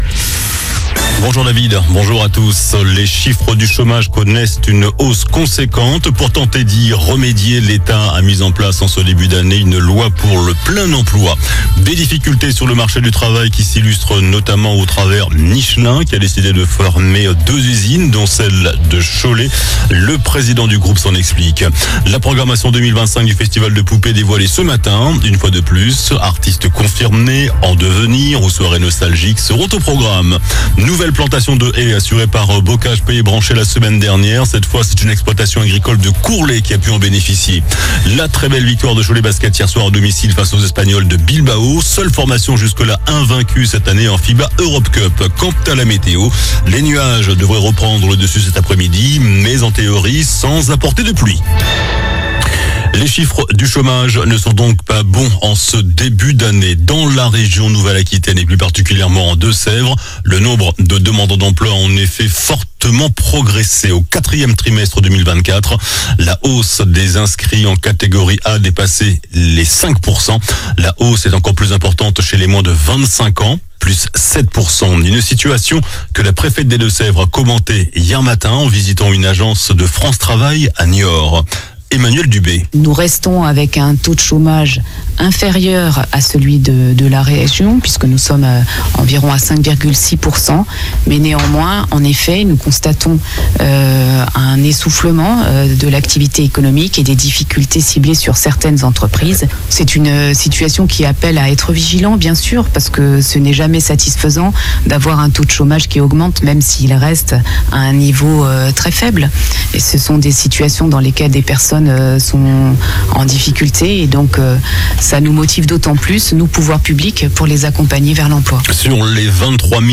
JOURNAL DU JEUDI 30 JANVIER ( MIDI )